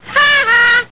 Laugh3.mp3